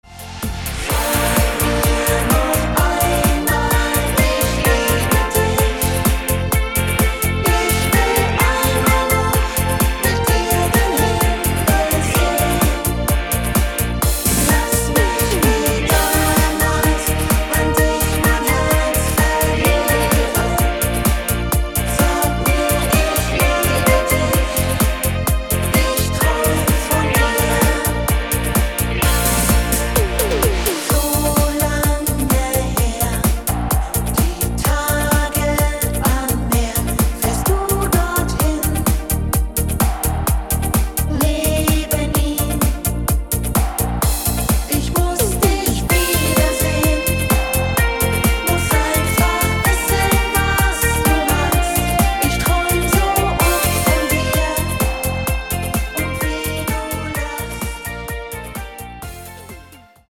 Rhythmus  Discofox